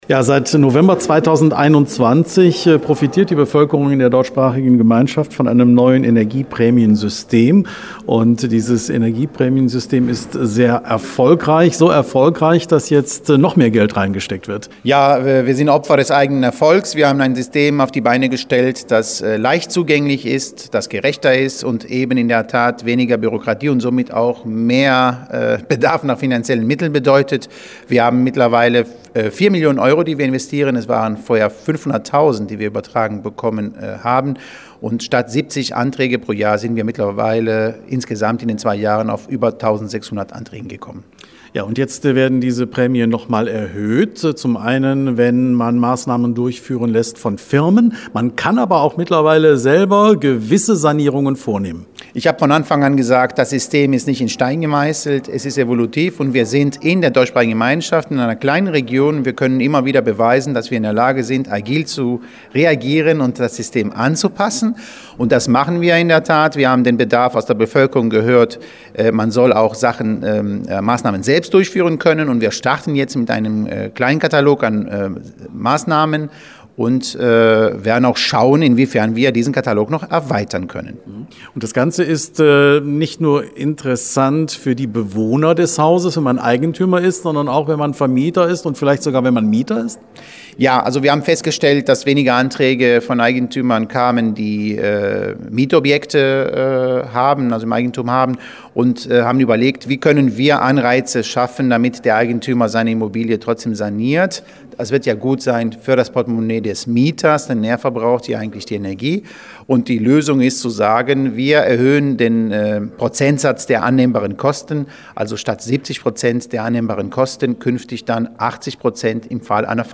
mit dem zuständigen Minister Antonios Antoniadis gesprochen